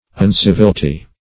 uncivilty - definition of uncivilty - synonyms, pronunciation, spelling from Free Dictionary Search Result for " uncivilty" : The Collaborative International Dictionary of English v.0.48: Uncivilty \Un*civ"il*ty\, adv.